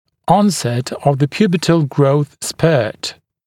[‘ɔnset əv ðə ‘pjuːbətl grəuθ spɜːt][‘онсэт ов зэ ‘пйу:бэтл гроус спё:т]наступление пубертатного скачка роста